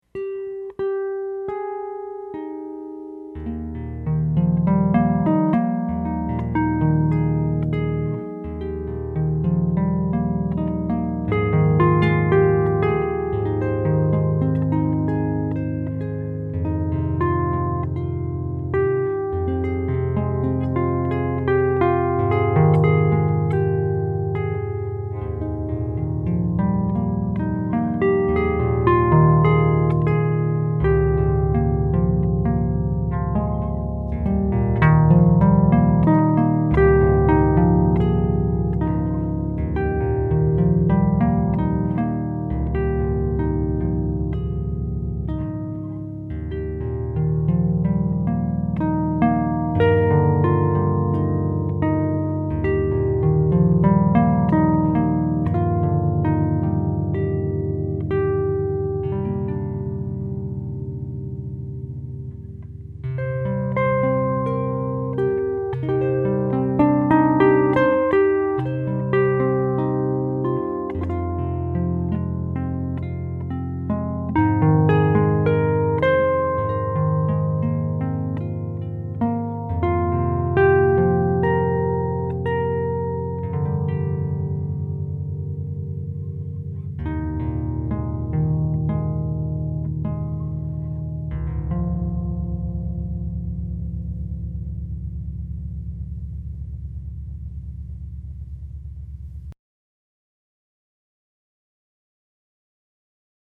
Harp